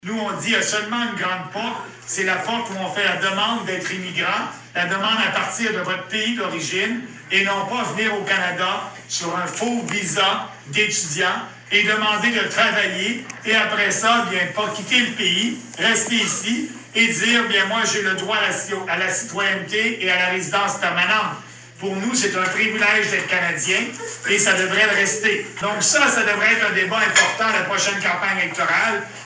Le chef du Parti populaire du Canada, Maxime Bernier, était de passage pour une rencontre publique qui se tenait au restaurant St-Hubert de Granby, mardi.
Pour l’occasion, près de 40 personnes étaient sur place pour entendre un discours du politicien en plus d’une période de questions-réponses avec le public.